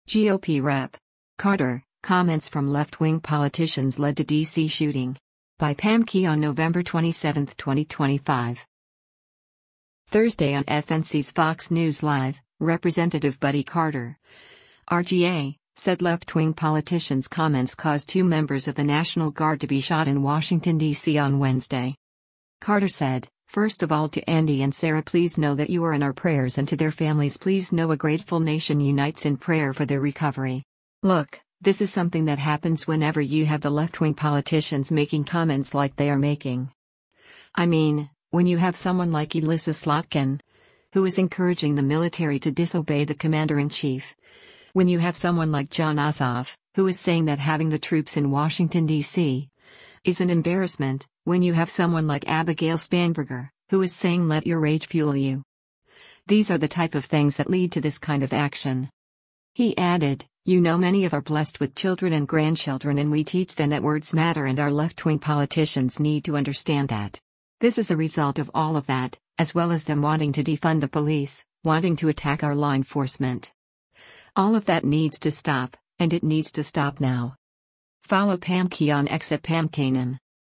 Thursday on FNC’s “Fox News Live,” Rep. Buddy Carter (R-GA) said “left-wing politicians” comments caused two members of the National Guard to be shot in Washington, D.C. on Wednesday.